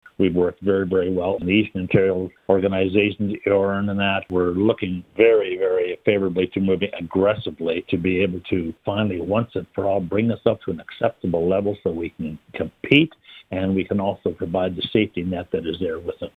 Kramp weighed in on the pandemic and more during a recent year in review interview with Quinte News.